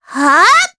Mediana-Vox_Attack3_jp.wav